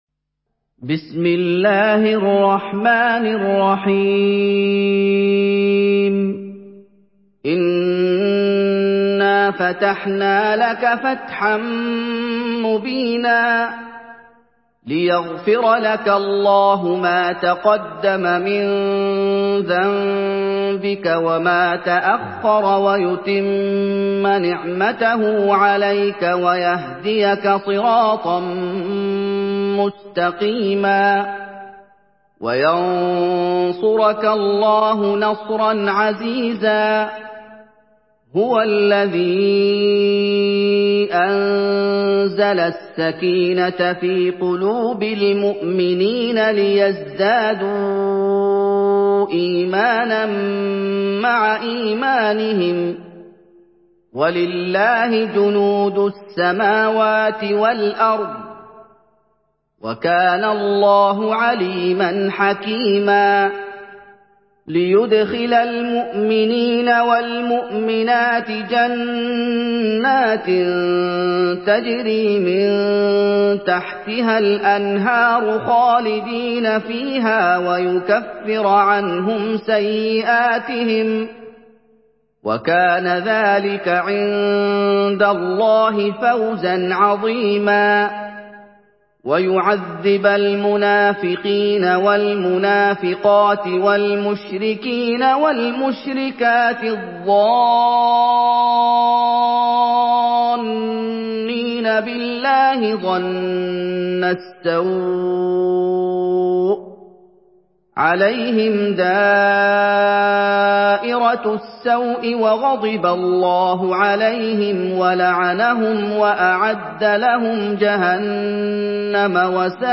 Une récitation touchante et belle des versets coraniques par la narration Hafs An Asim.